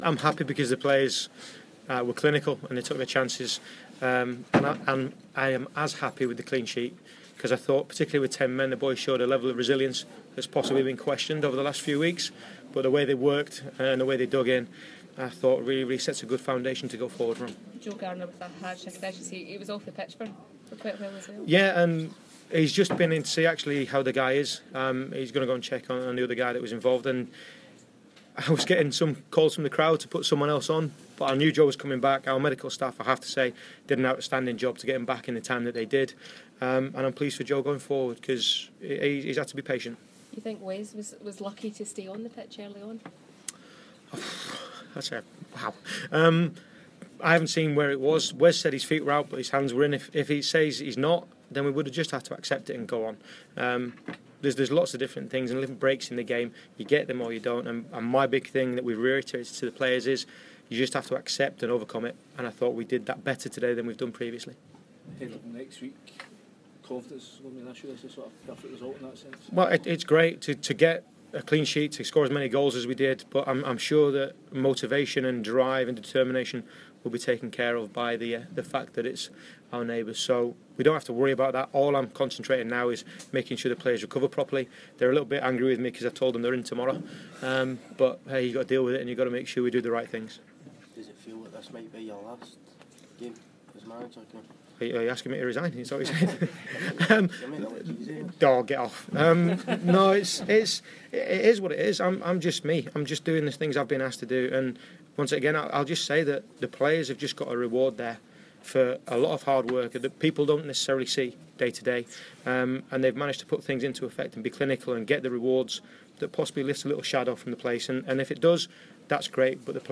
Listen to the Post Match Audio from Rangers 6 Hamilton 0